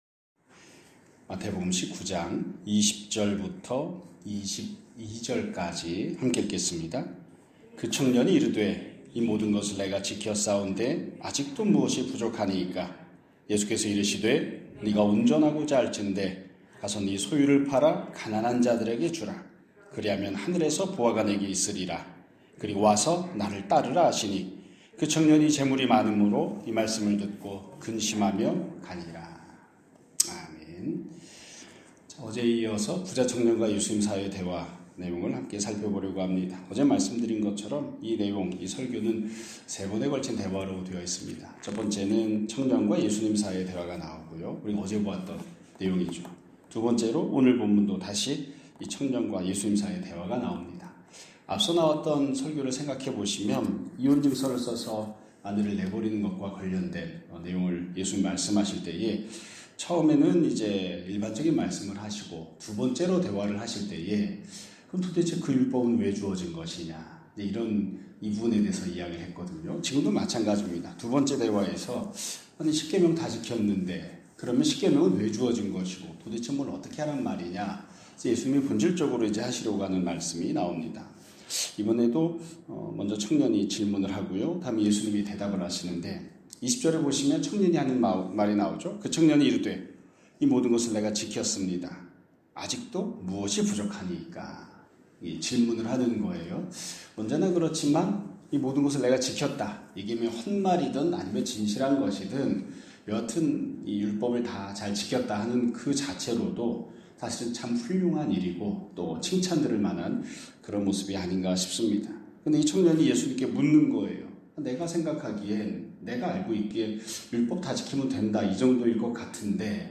2026년 1월 8일 (목요일) <아침예배> 설교입니다.